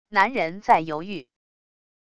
男人在犹豫wav音频